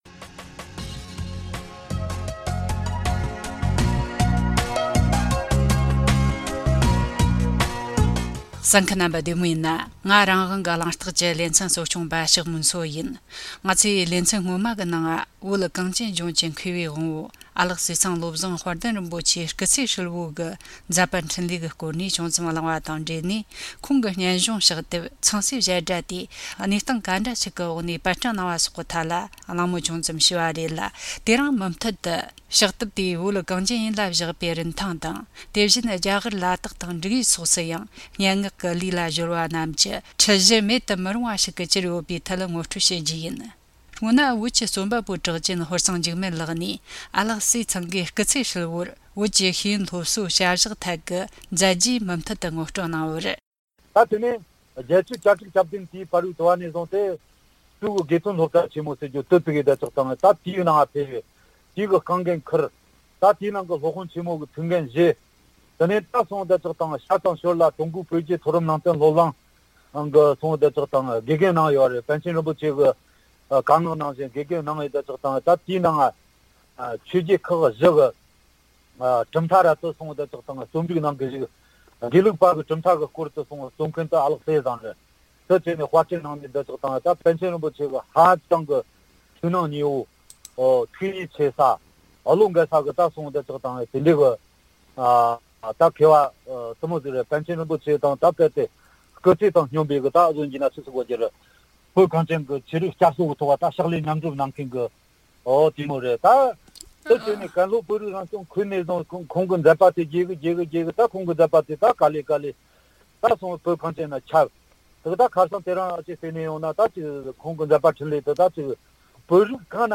གླེང་མོལ་བྱས་བར་གསན་རོགས་གནོངས།